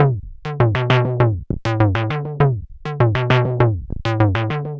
tx_synth_100_blobby_CBbEb.wav